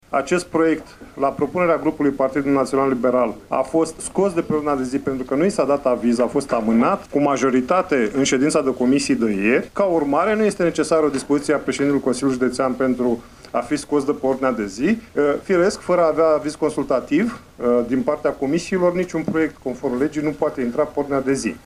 Preluarea clădirii Casei Studenţilor din Iaşi de către Consiliul Judeţean a născut astăzi dispute aprige în şedinţa forului condus de Maricel Popa.
Preşedintele grupului consilierilor liberali Romeo Vatră a precizat că iniţiativa  nu a primit avizul comisiilor de specialitate şi ca atare nu trebuia să figureze pe ordinea de zi de astăzi.